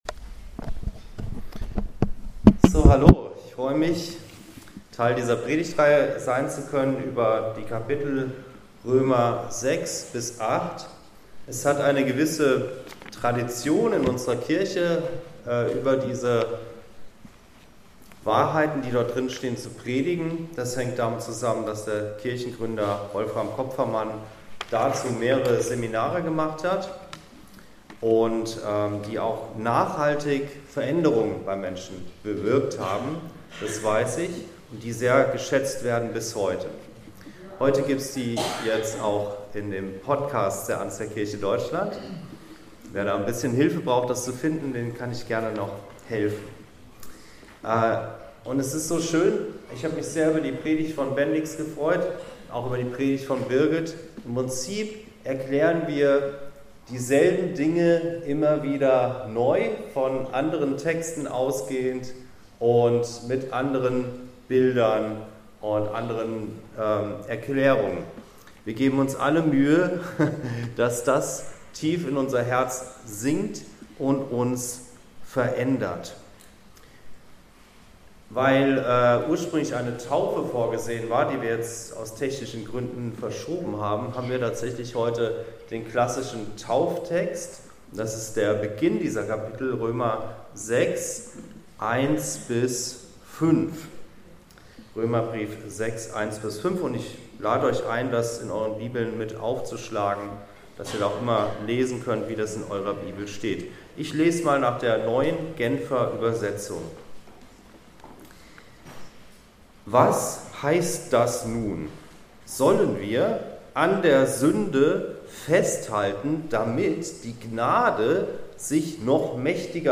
Frei von dir selbst ~ Anskar-Kirche Hamburg- Predigten Podcast